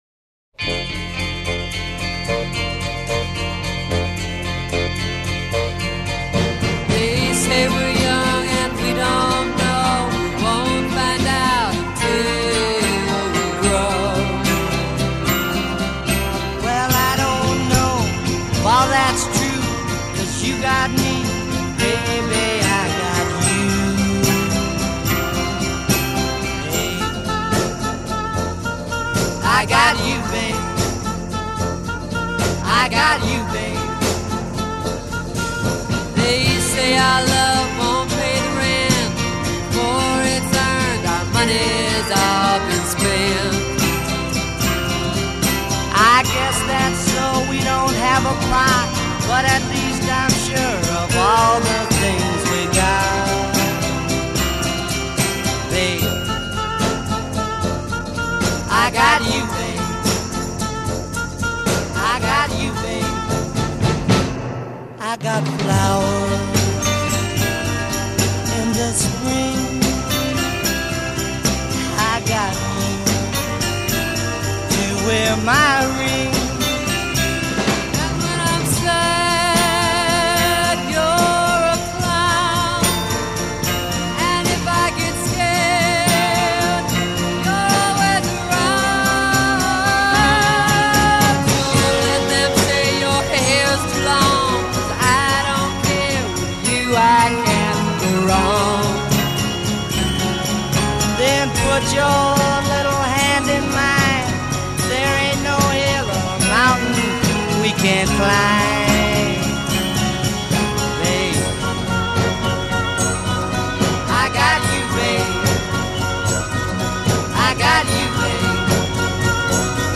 Главная » Рингтоны » Рингтоны на будильник